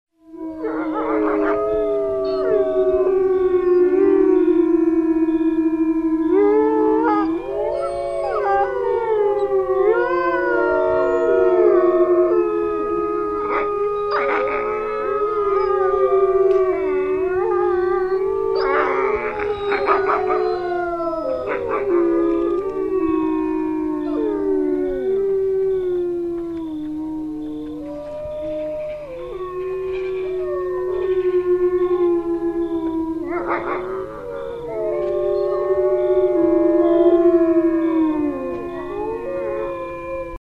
Звук воя волчьей стаи